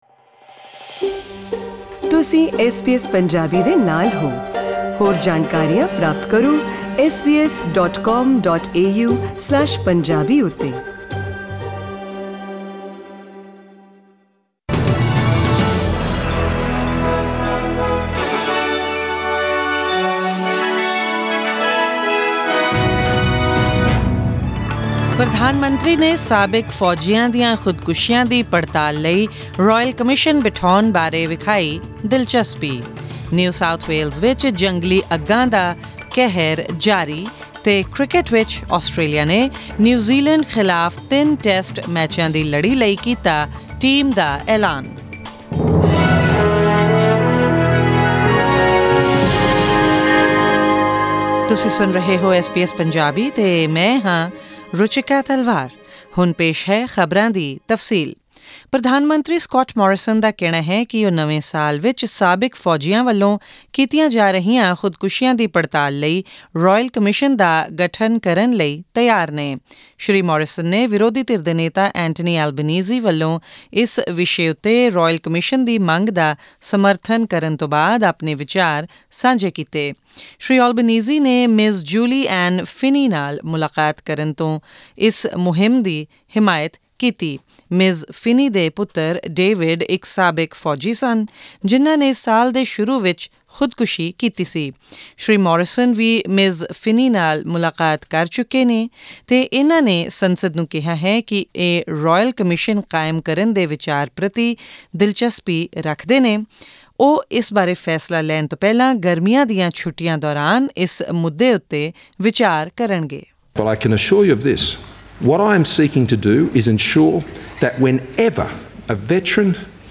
SBS Punjabi News: December 3, 2019